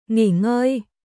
nghỉ ngơi休憩するンギー ンゴイ